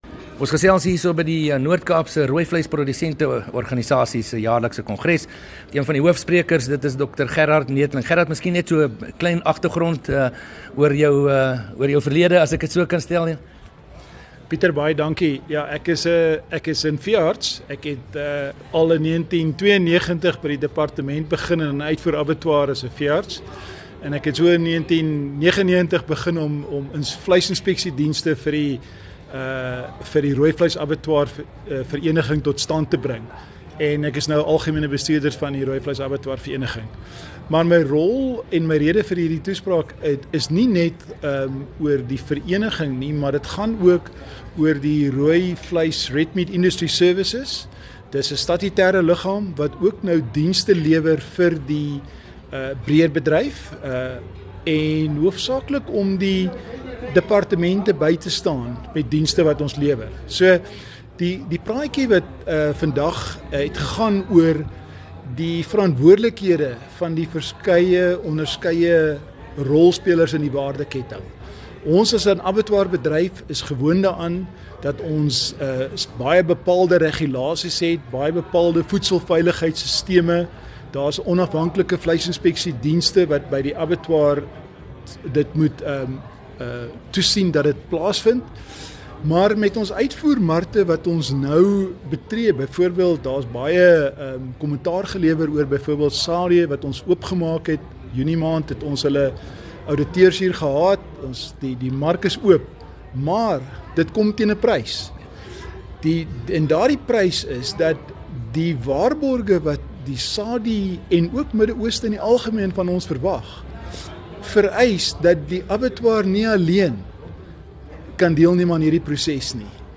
op die pas afgelope kongres van die Noord-Kaap se RPO